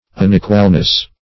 unequalness - definition of unequalness - synonyms, pronunciation, spelling from Free Dictionary
Search Result for " unequalness" : The Collaborative International Dictionary of English v.0.48: Unequalness \Un*e"qual*ness\, n. The quality or state of being unequal; inequality; unevenness.
unequalness.mp3